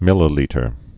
(mĭlə-lētər)